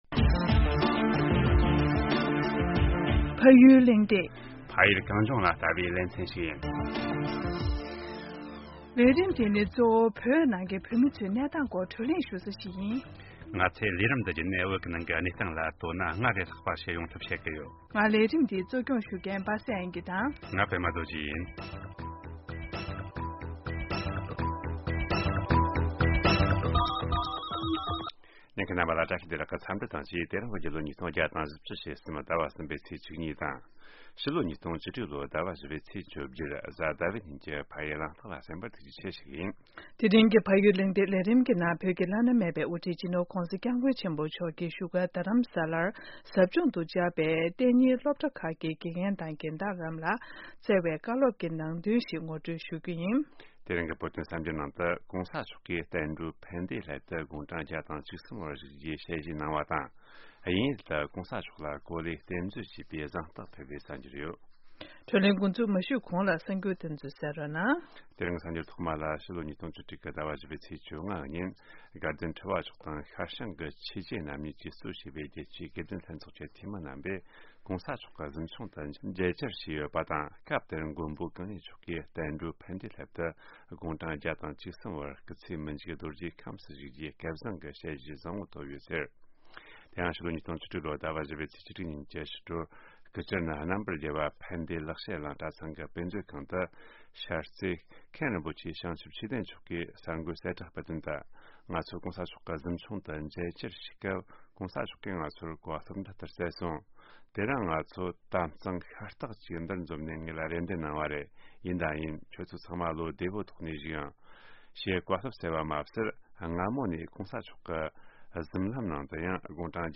བོད་ཀྱི་བླ་ན་མེད་པའི་དབུ་ཁྲིད་༧གོང་ས་སྐྱབས་མགོན་ཏཱ་ལའི་བླ་མ་མཆོག་གིས་བཞུགས་སྒར་དྷ་རམ་ས་ལར་ཟབ་སྦྱོང་དུ་བཅར་བའི་གཏན་ཉིན་སློབ་གྲྭ་ཁག་གི་རྒན་བདག་དང་དགེ་རྒན་རྣམས་ལ་གཟིམ་ཆུང་མཇལ་ཁ་གནང་སྟེ། དགེ་སློབ་རྣམས་ལ་འཛམ་གླིང་གི་དུས་རབས་ཉི་ཤུ་པ་དེ་འཚེ་བའི་དུས་རབས་ཤིག་གི་ཐོག་ཏུ་ཕྱིན་ཡོད་པས་དུས་རབས་༢༡་པ་འདི་འཚེ་བ་མེད་པའི་དུས་རབས་ཤིག་ལ་བསྒྱུར་དགོས་པ་སོགས་ཀྱི་བཀའ་སློབ་སྩལ་ཡོད་པ་རེད།།